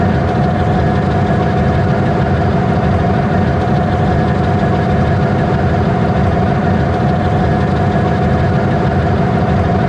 DieselLocomotiveMotor.wav